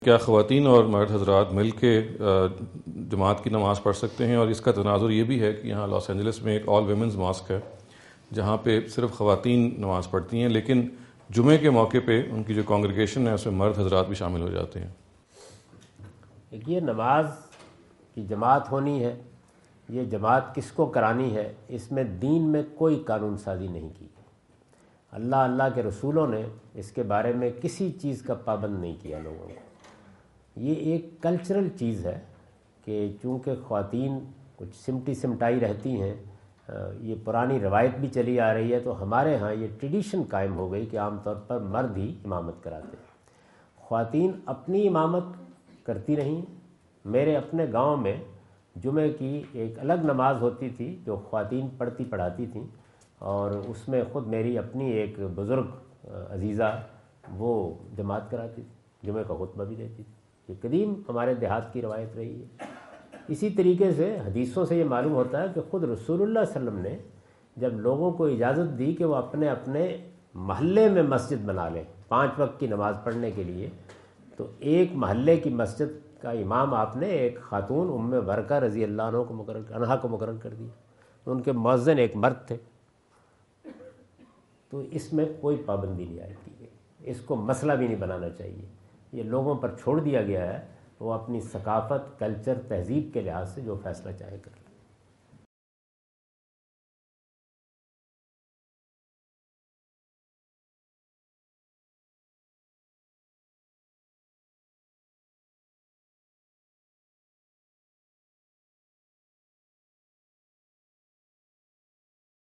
Category: English Subtitled / Questions_Answers /
Javed Ahmad Ghamidi answer the question about "Can Men and Women Offer Congregational Prayer Together" asked at Corona (Los Angeles) on October 22,2017.